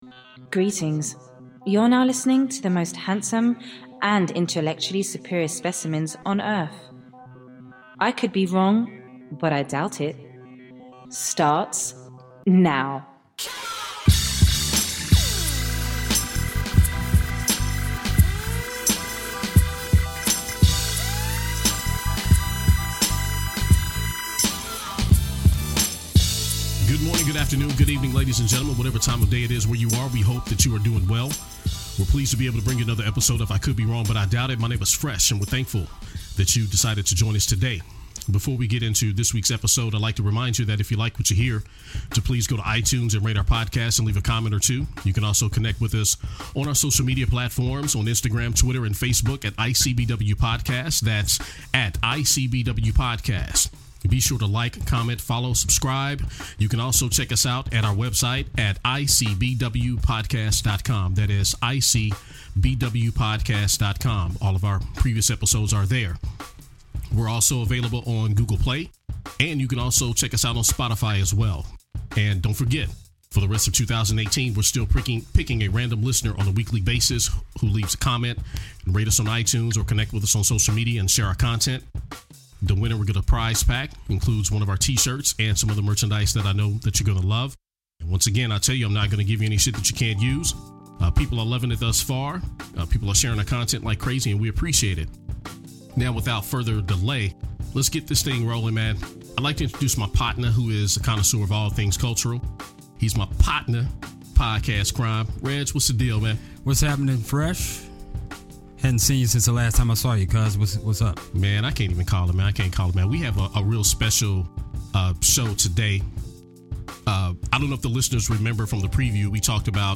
This week's episode is special because we have a special guest.